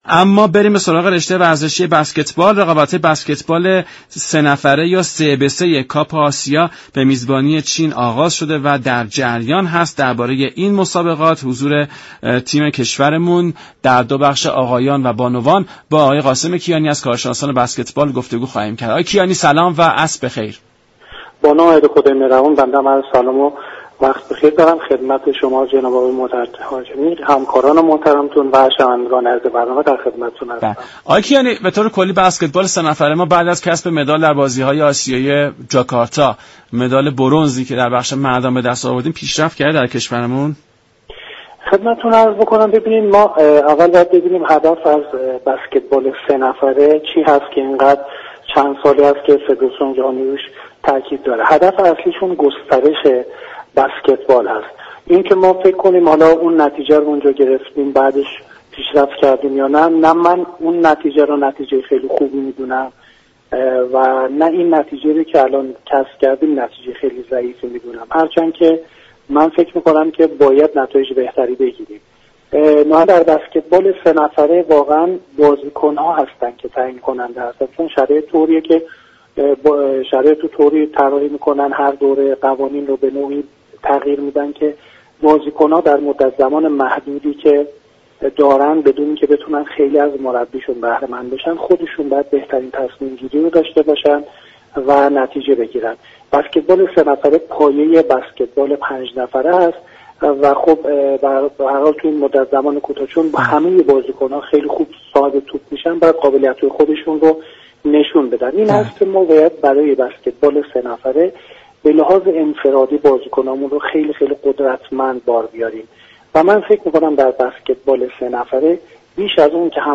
در گفت و گو با برنامه ورزش ایران